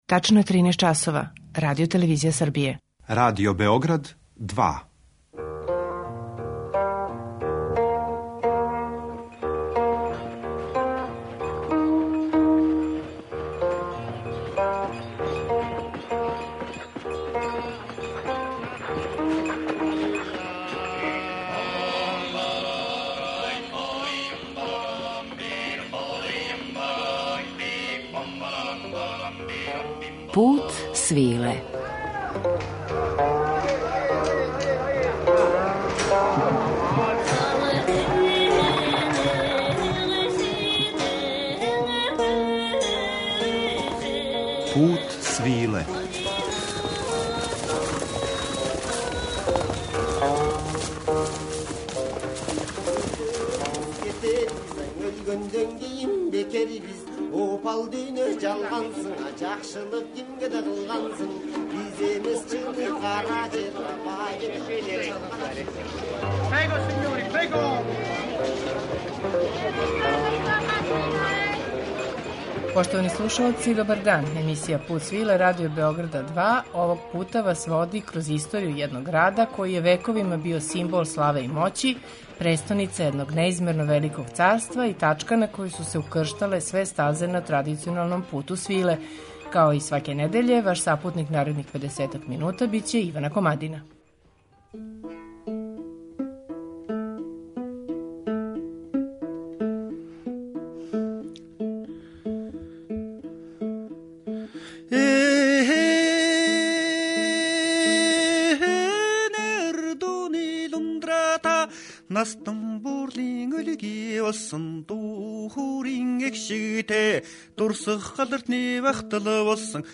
Пут свиле данас вас води кроз историју овог града који је вековима био симбол славе и моћи, тачка на којој су се укрштале све стазе на традиционалном Путу свиле. У музичком раму за портрет Самарканда укрстићемо неке од бројних музичких традиција које су доспеле у овај град - узбечку, персијску, казашку, грчку...